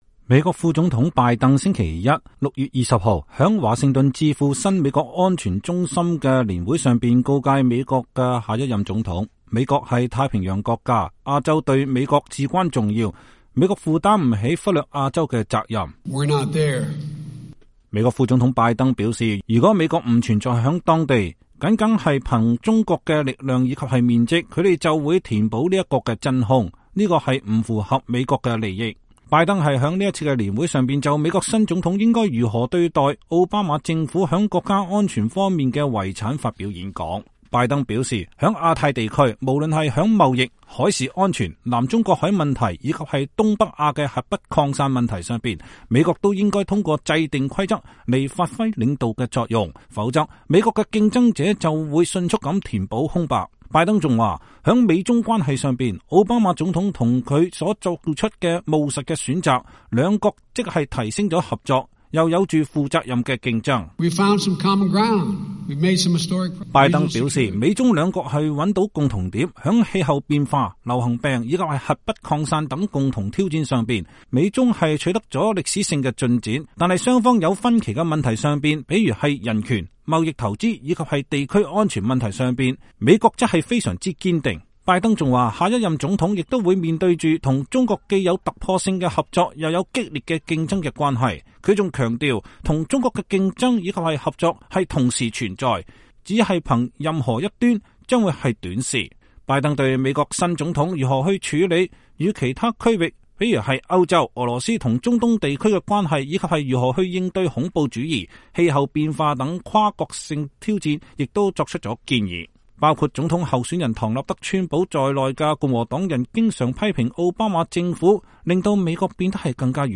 美國副總統拜登星期一在華盛頓智庫新美國安全中心發表演講。